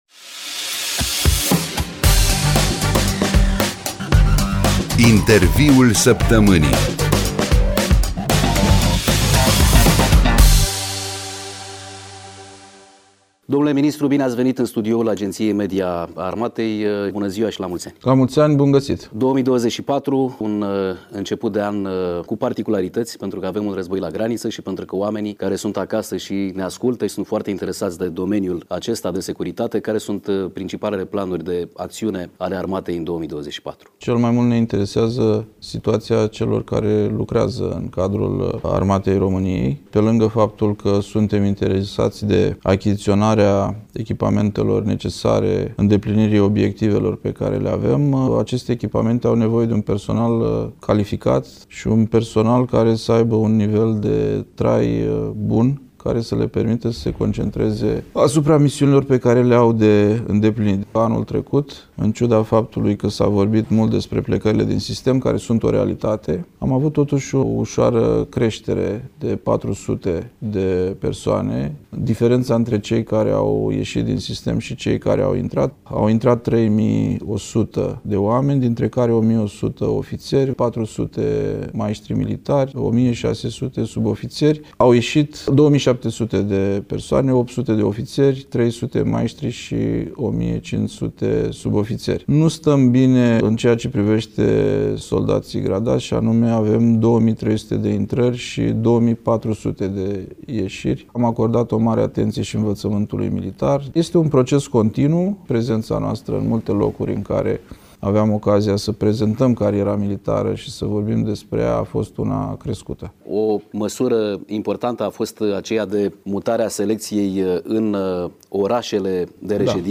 Interviul săptămânii